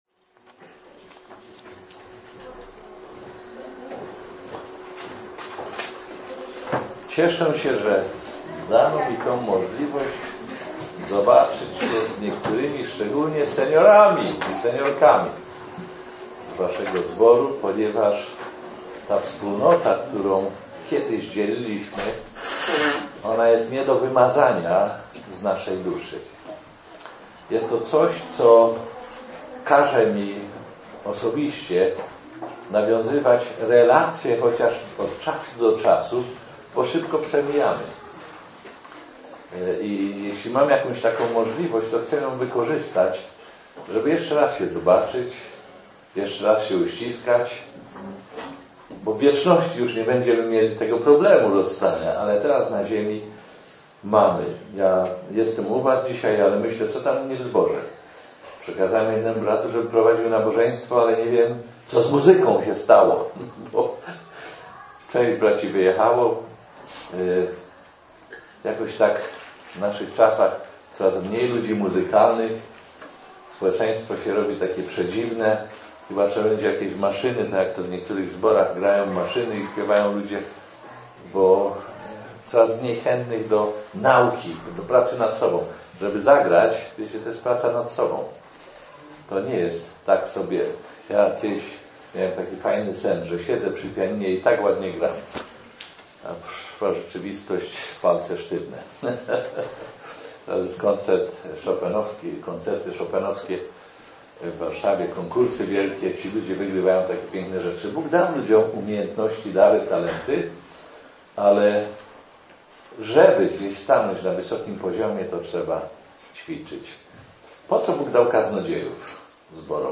Archiwa Kazania - Strona 4 z 4 - Kosciół Ewangelicznych Chrześcijan